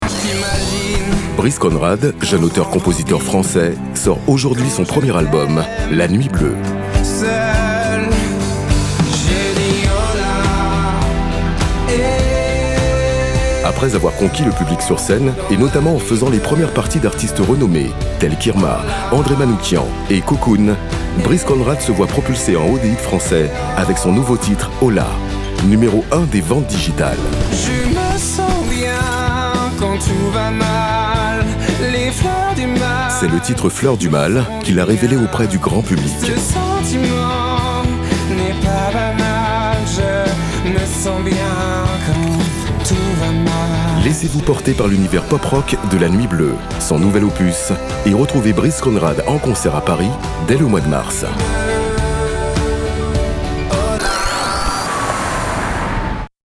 VOIX OFF, animateur radio
Sprechprobe: eLearning (Muttersprache):